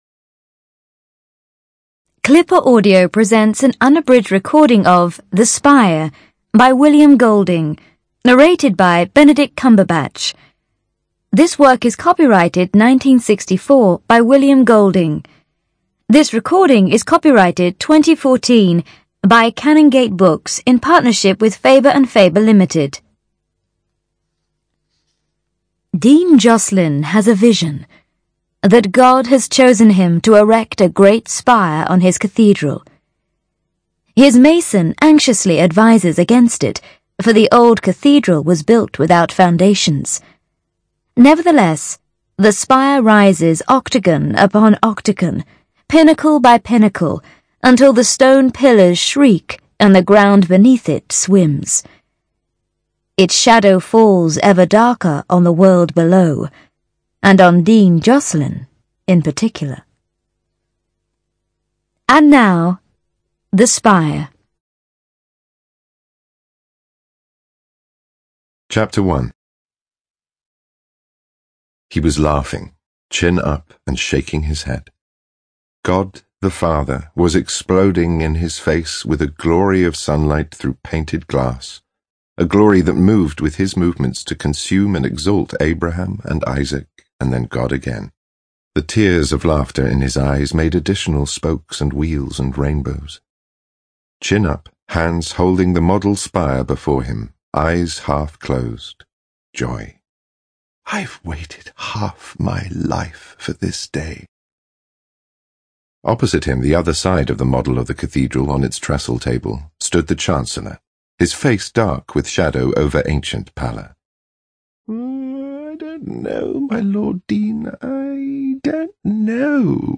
ЧитаетКамбербач Б.